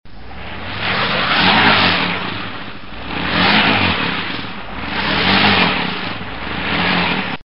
Звуки легкового автомобиля
Звук застрявшей машины на льду с пробуксовкой колеса